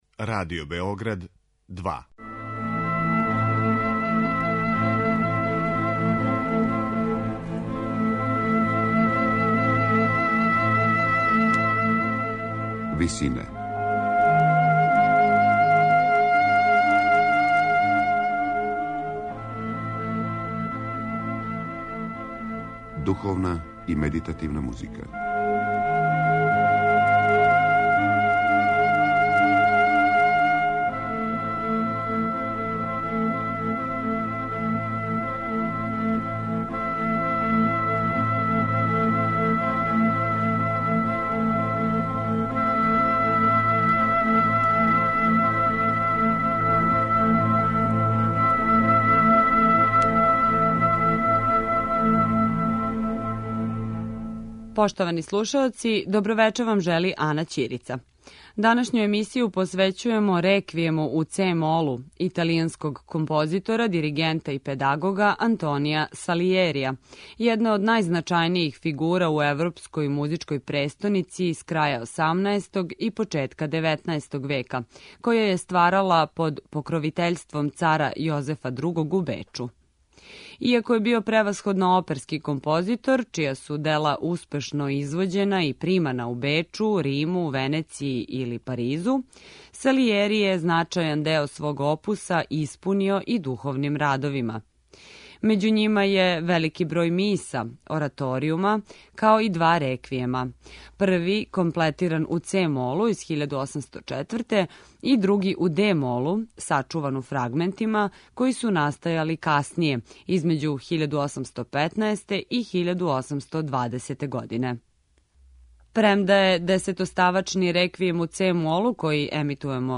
Данашњу емисију посвећујемо Реквијему у це-молу за солисте, хор и оркестар, који је 1804. године написао италијански композитор, диригент и педагог Антонио Салиери.
у ВИСИНАМА представљамо медитативне и духовне композиције аутора свих конфесија и епоха.